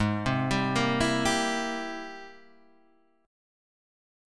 G#+M9 chord